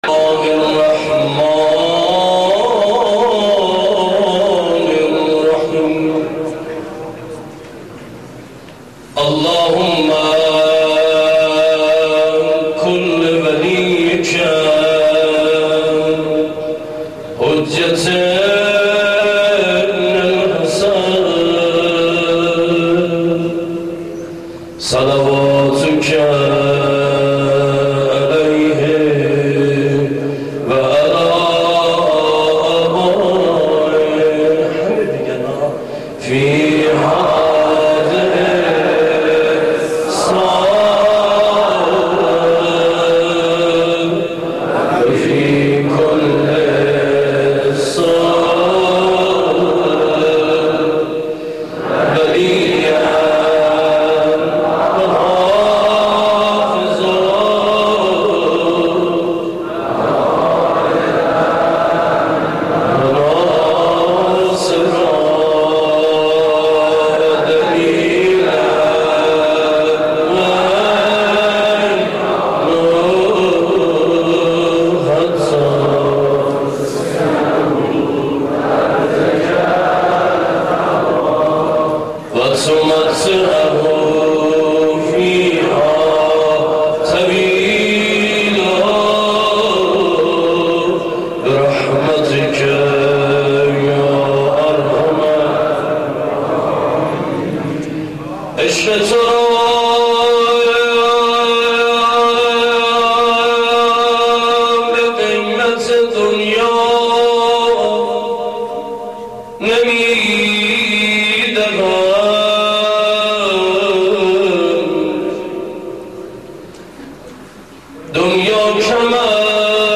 روضه حضرت علی اصغر علیه السلام